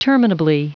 Prononciation du mot terminably en anglais (fichier audio)